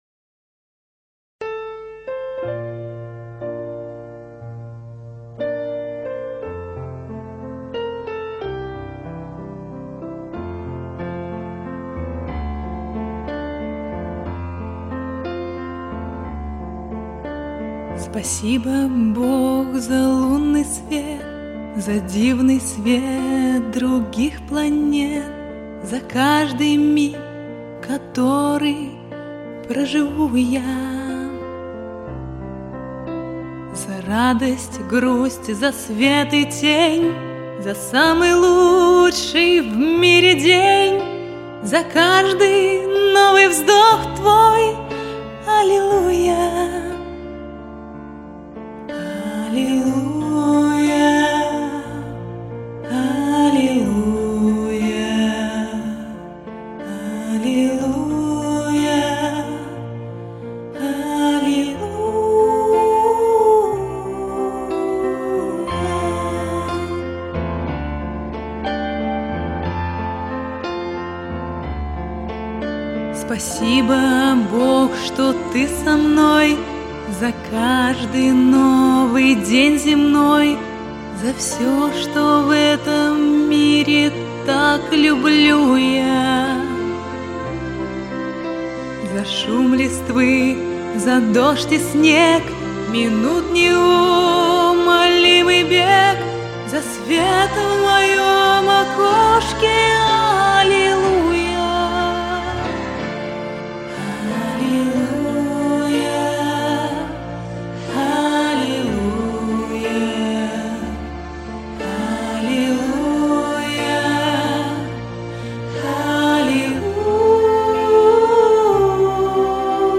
Пою, вокал - альт.
Меццо-сопрано